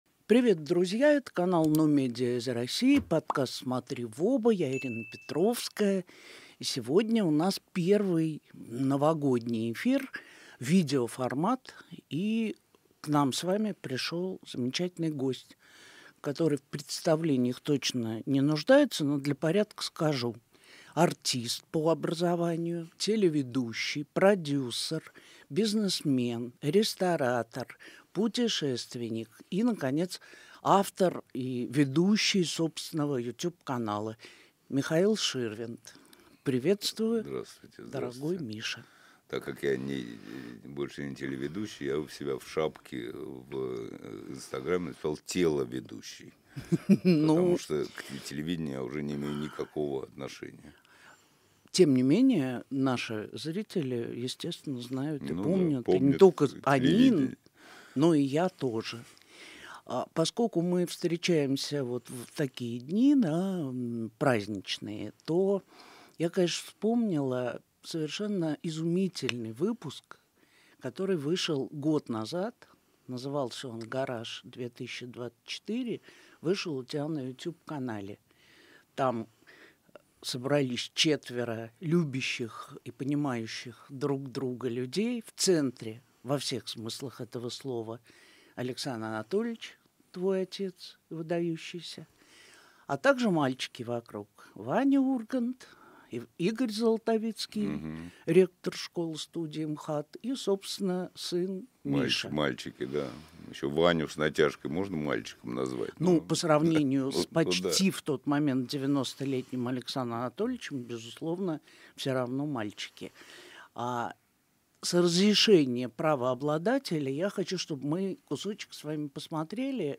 Подкаст ведёт Ирина Петровская